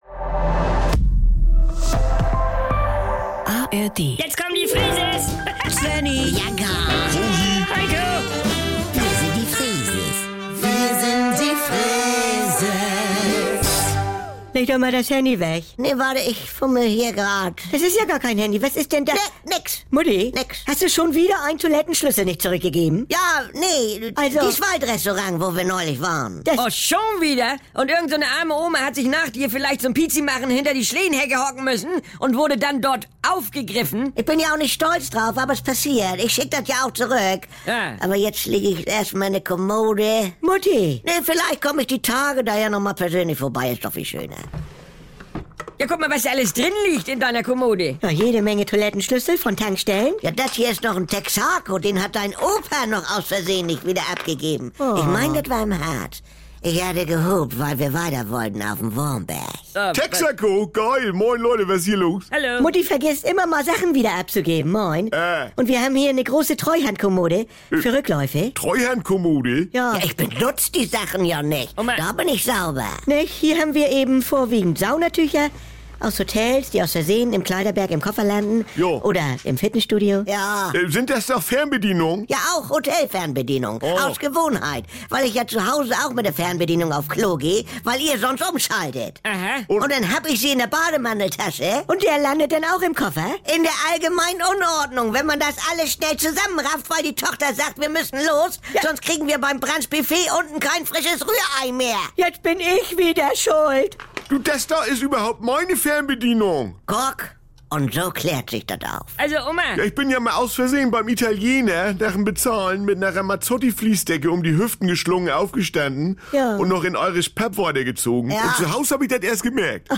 Saubere Komödien NDR 2 Komödie Unterhaltung NDR Freeses Comedy